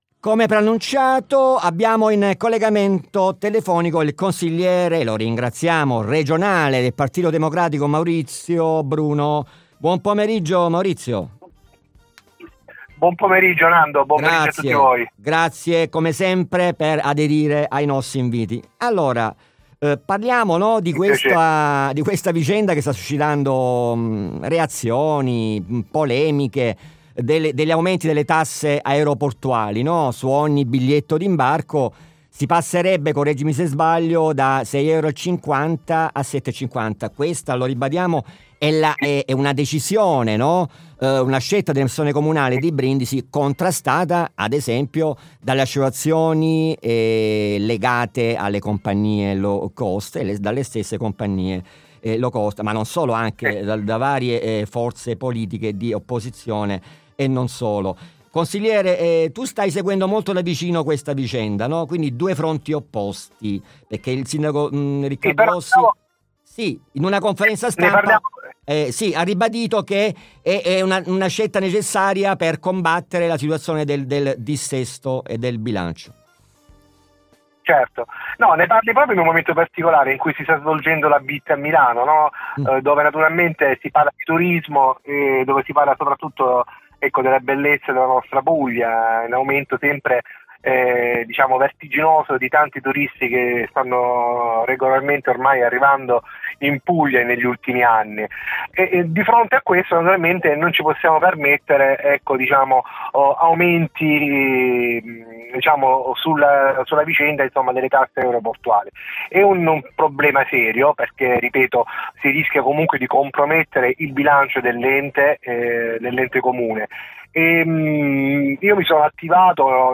Ospite di questa puntata Maurizio Bruno, Consigliere Regionale del Partito Democratico ...
Interviste